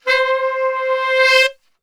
C 3 SAXSWL.wav